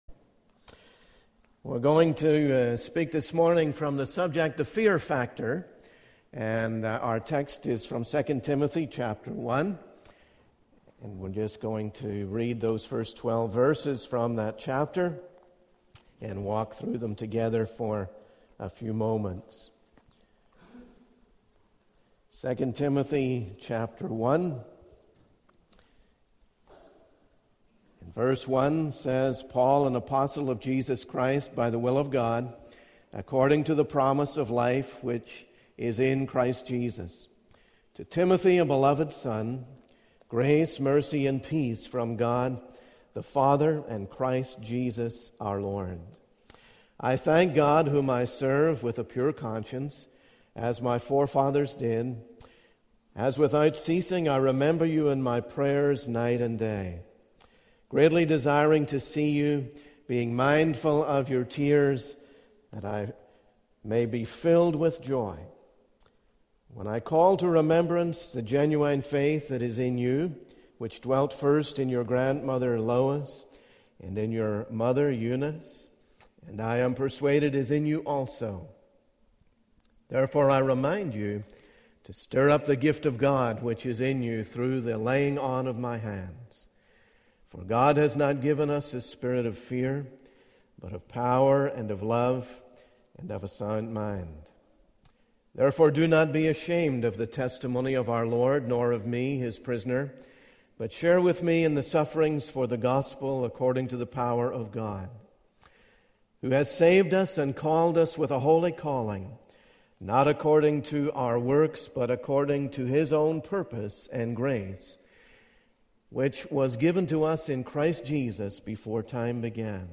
In this sermon, the speaker discusses the concept of fear and its consequences in our lives. He references Bible verses such as James 4:14 and Ecclesiastes to emphasize the fleeting nature of life and the vanity of worldly pursuits. The speaker also highlights the importance of having a spirit of love rather than fear, as God has given us the spirit of love.